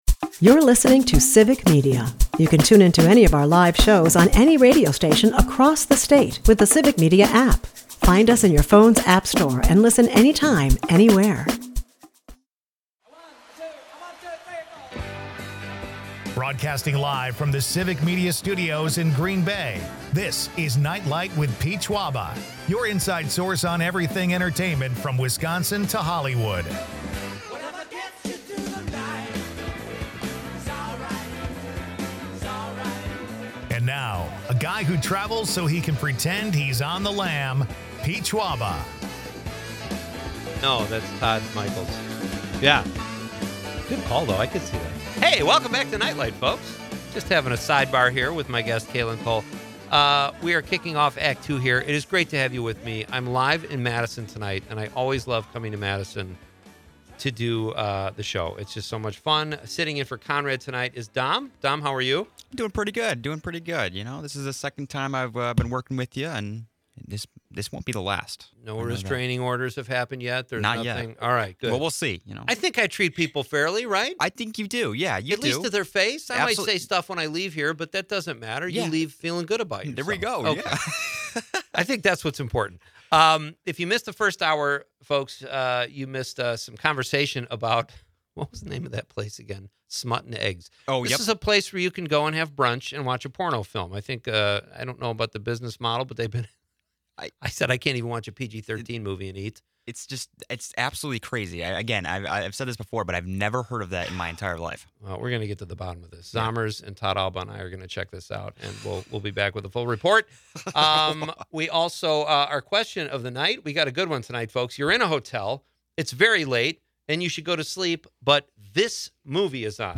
is a part of the Civic Media radio network and airs Monday through Friday from 6-8 pm across Wisconsin